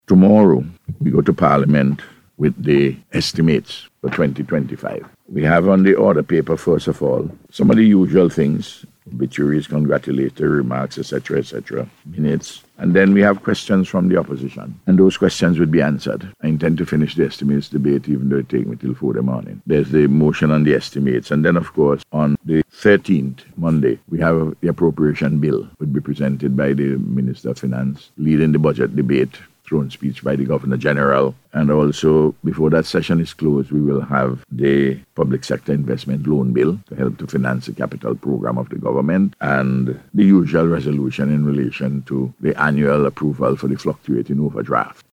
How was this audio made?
He made this statement during the face to face program aired on NBC Radio this morning.